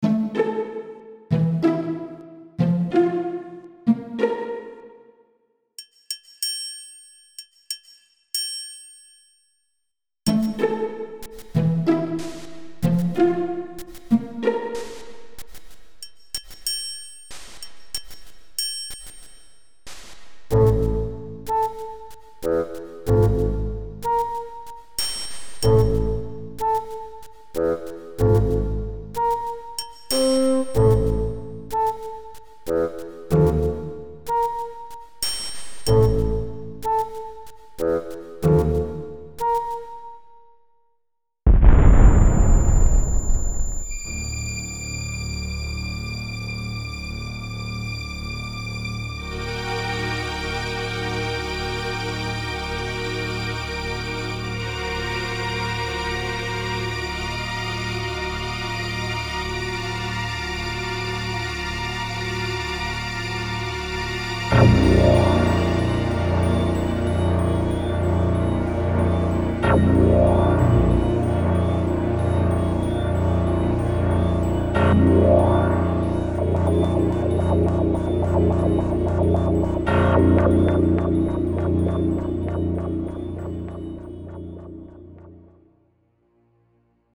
Compo 6 has the power of sound effects, which reminded me how I like good SFx. I was very surprised about the beautiful harmonies, but we'll come to that later.